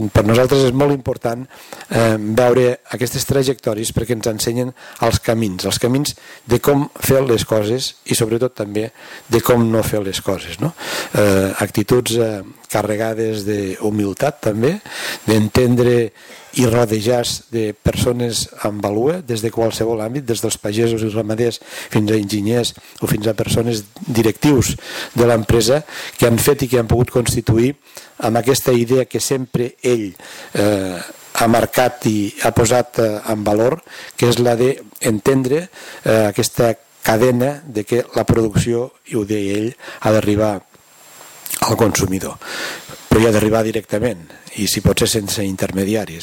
El nou premi de reconeixement a projectes innovadors ha estat per Arantec Enginheria amb el projecte Smart River La sala d’actes de la Diputació de Lleida ha acollit aquest dijous la 15a edició del lliurament dels Premis LO als millors projectes empresarials de l’anualitat 2013 amb l’objectiu de reconèixer la innovació i l’emprenedoria d’empresaris lleidatans que han destacat per la feina feta en cadascuna de les seves activitats.